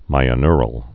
(mīə-nrəl, -nyr-)